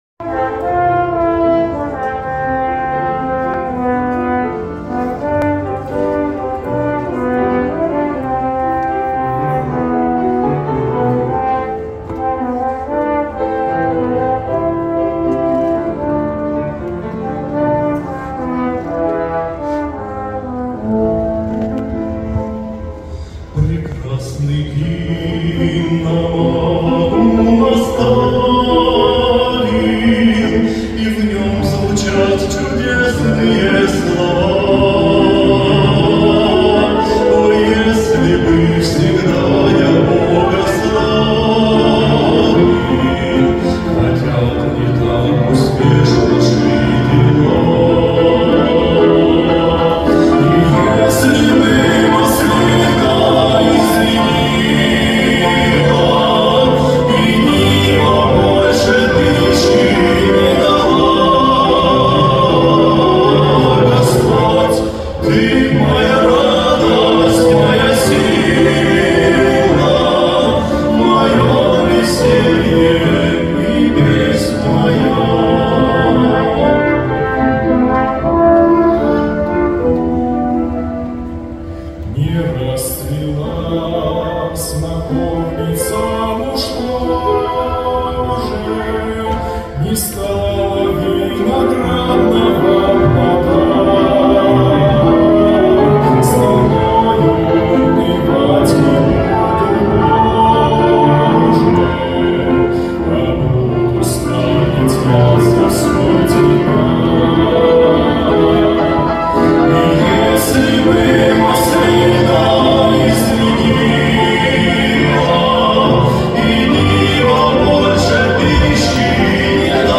160 просмотров 138 прослушиваний 8 скачиваний BPM: 75